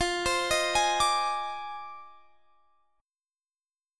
Fm13 Chord
Listen to Fm13 strummed